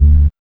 2708R BASS.wav